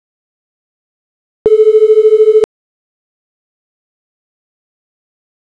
When the two pitches get close to a JND, they start to create auditory beat frequencies.
FIGURE 1.13. Variation of pitch in relationship to an in tune pitch.